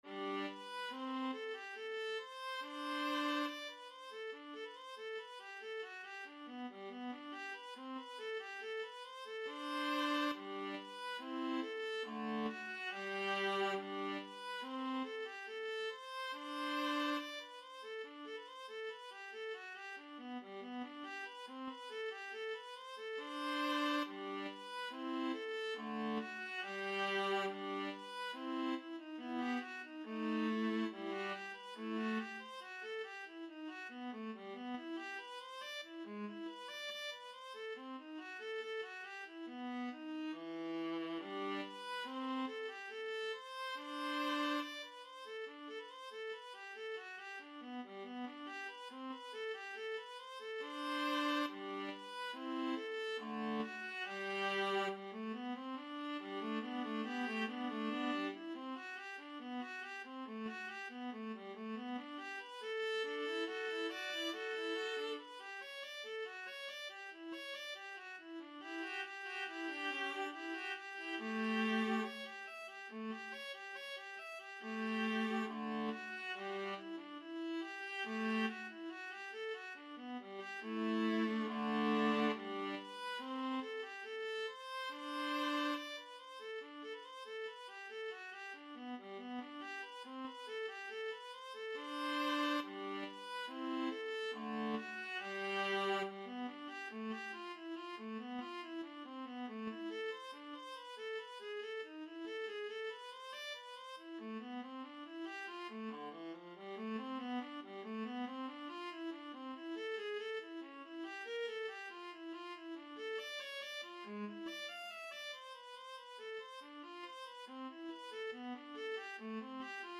Classical Bach, Johann Sebastian Violin Partita No.3 in E major Viola version
G major (Sounding Pitch) (View more G major Music for Viola )
2/2 (View more 2/2 Music)
Viola  (View more Advanced Viola Music)
Classical (View more Classical Viola Music)
violin-partita-3-in-e_3_gavotte_VLA.mp3